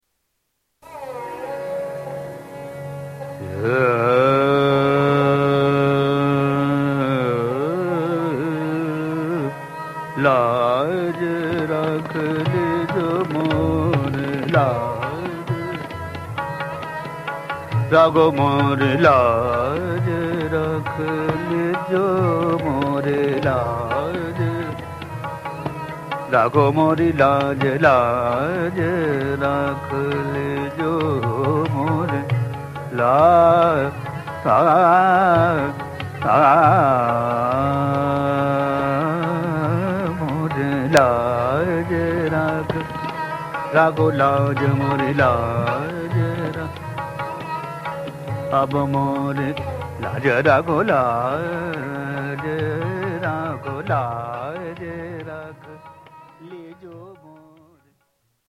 Indian Music